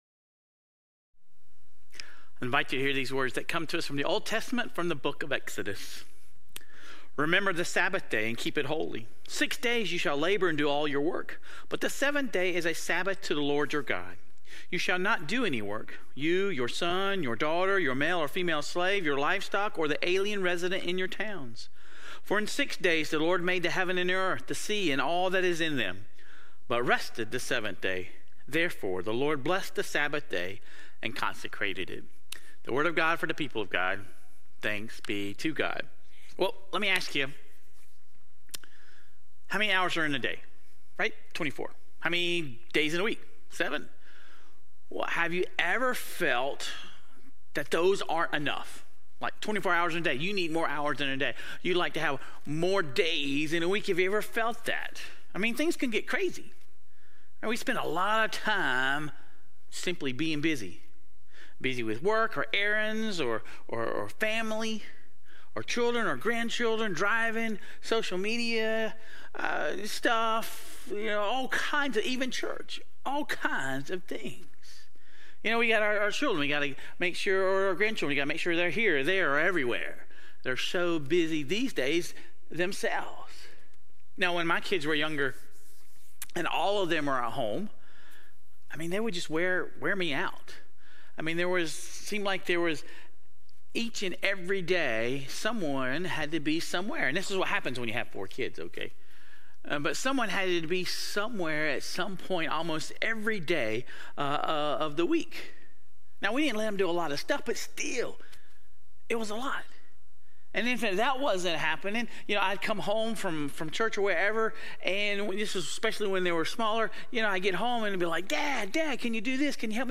Sermon Reflections: How do you personally define "Sabbath" in your own life?